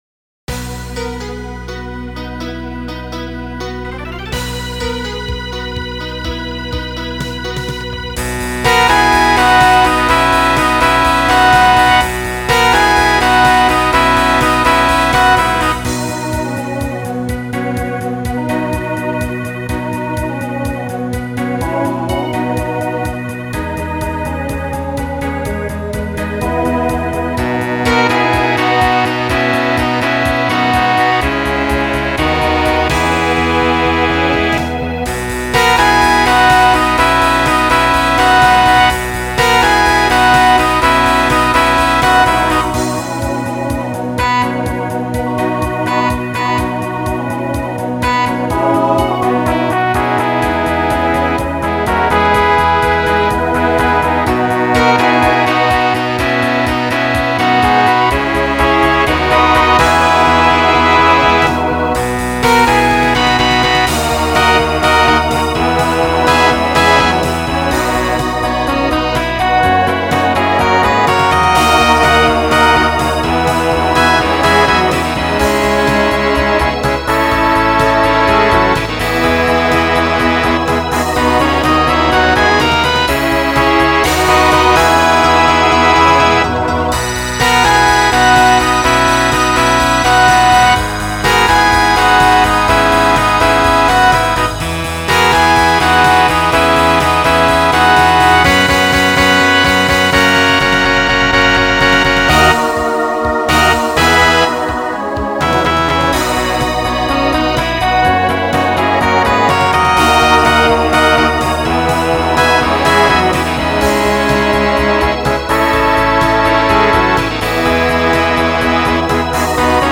Voicing SATB Instrumental combo Genre Country , Rock